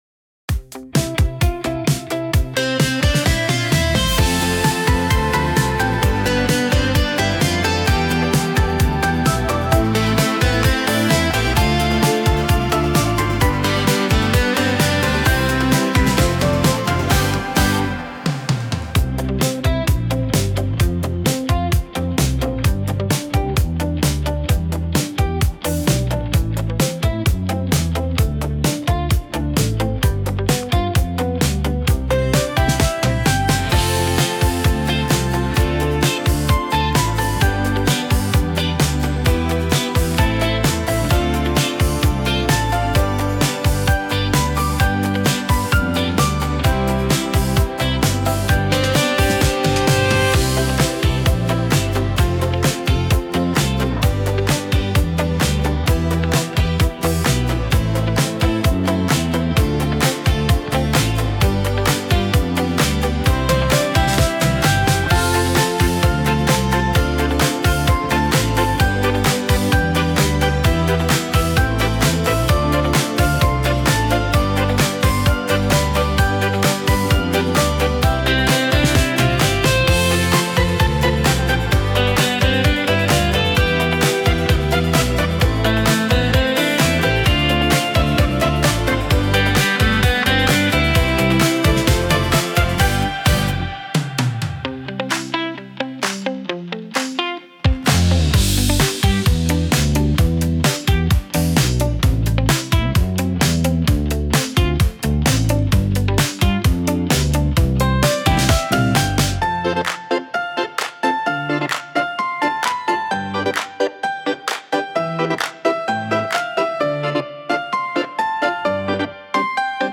Tone Nữ (C)
KARAOKE